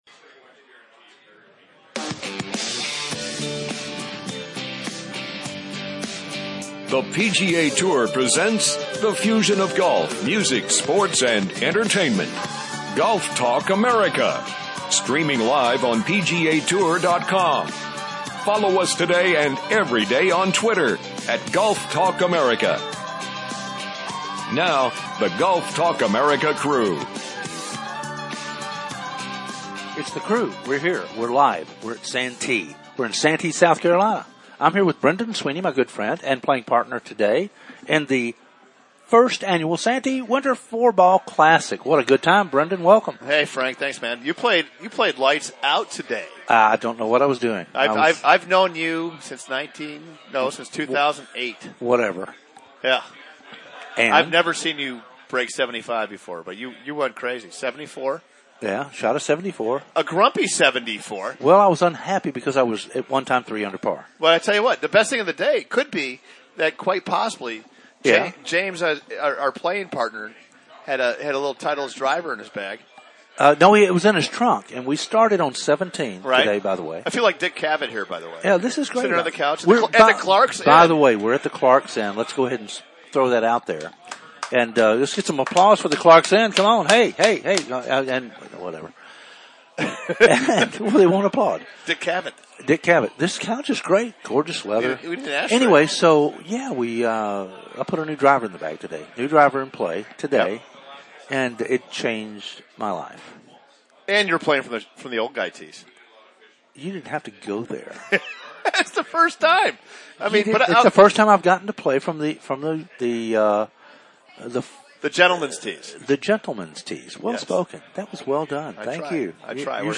Day 2 "LIVE" from The SANTEE WINTER CLASSIC